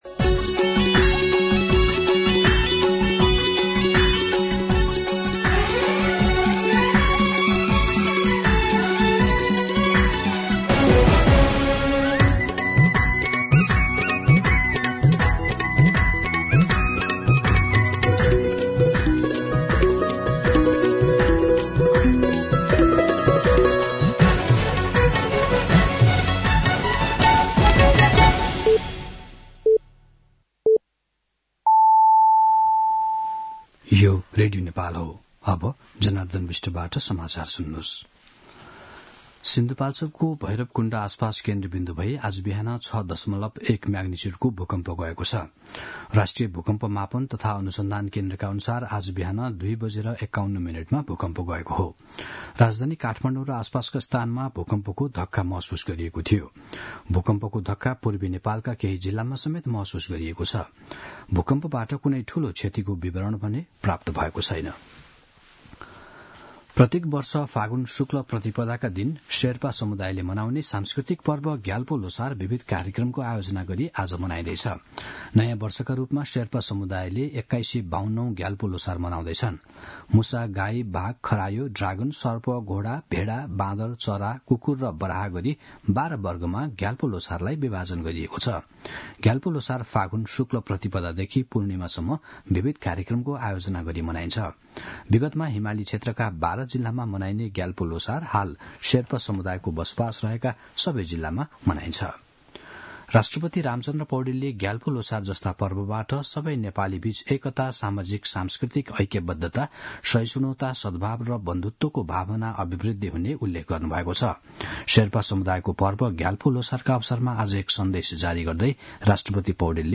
दिउँसो १ बजेको नेपाली समाचार : १७ फागुन , २०८१